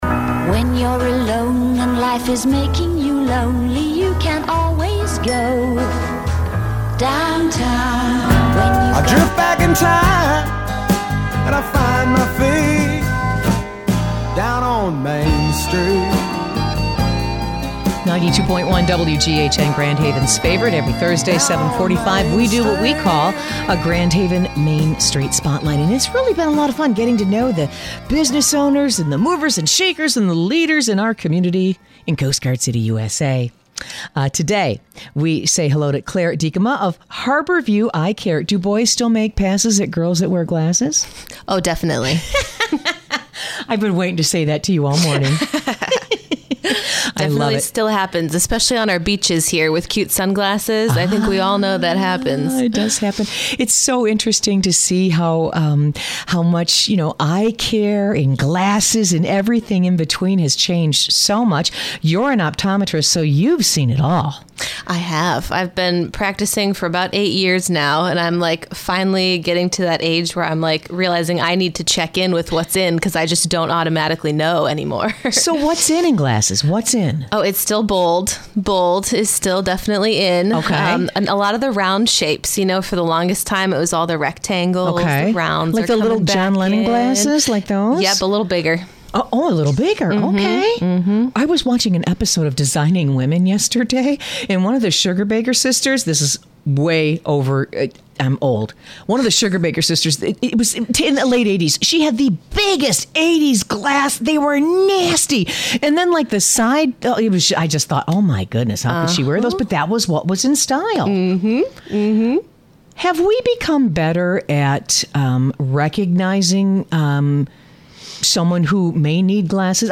A very informative interview on eyecare.